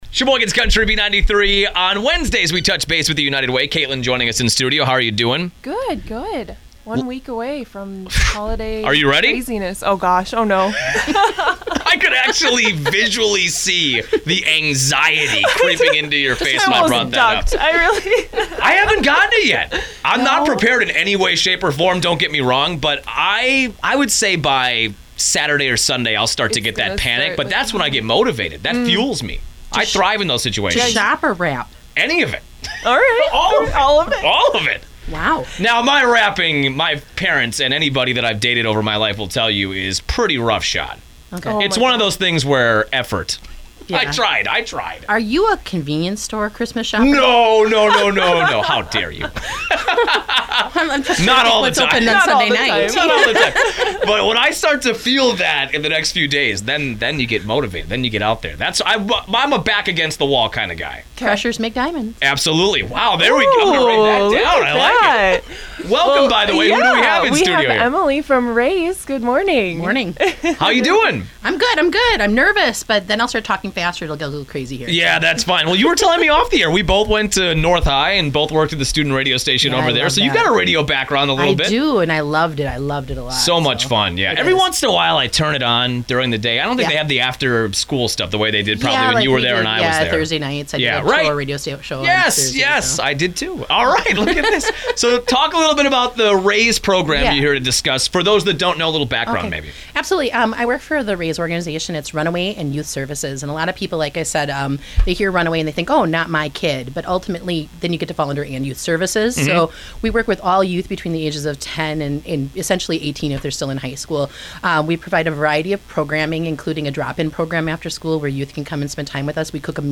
Lutheran Social Services-RAYS Radio Spot
Thank you to Midwest Communications for sponsoring the weekly radio spot on WHBL and B93 Sheboygan’s Country Radio Station!